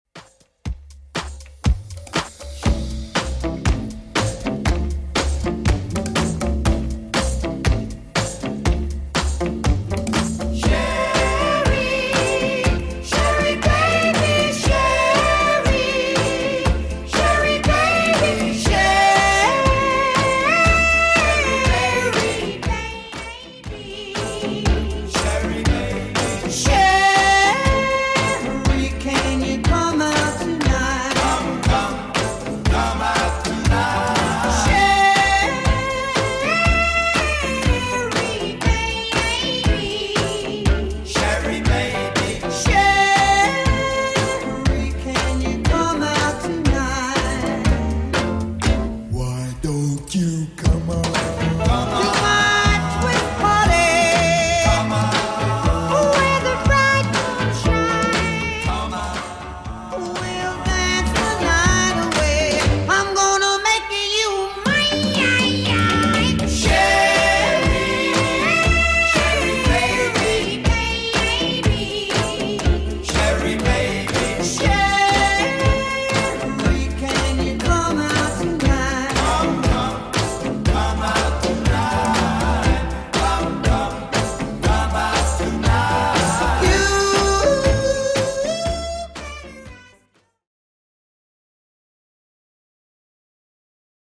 BPM: 120 Time